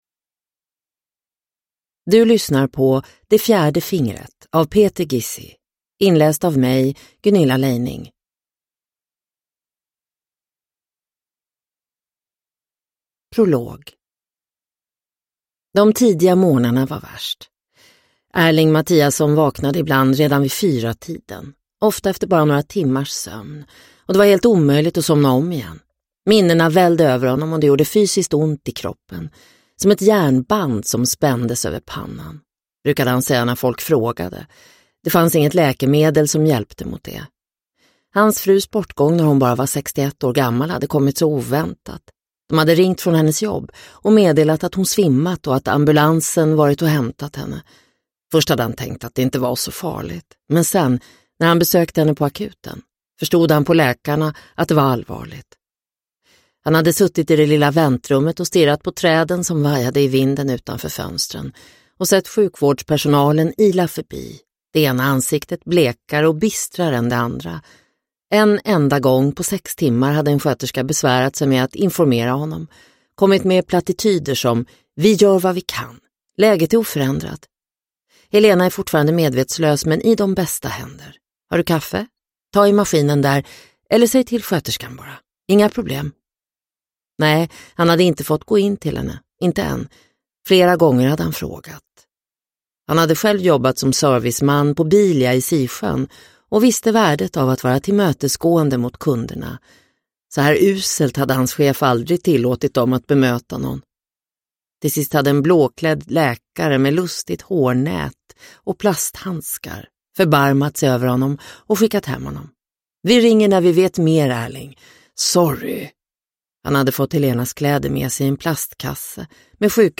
Det fjärde fingret – Ljudbok – Laddas ner